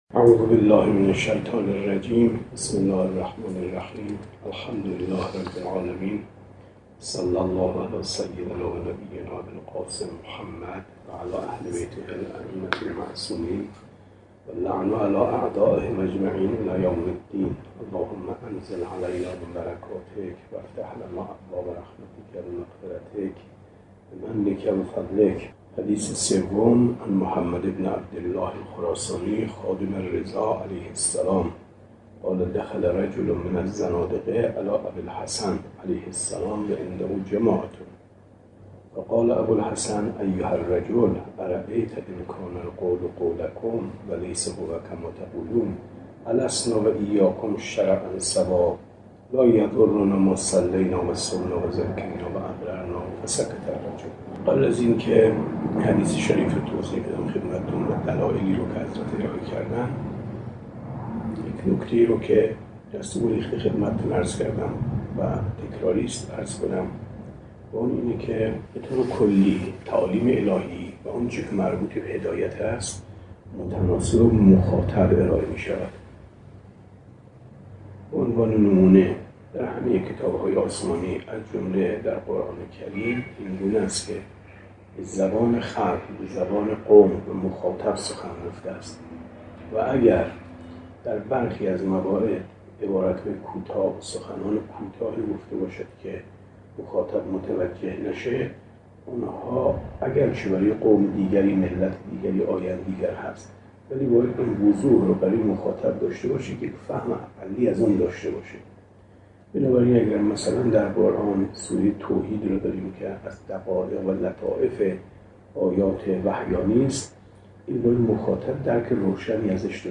شرح اصول کافی درس 129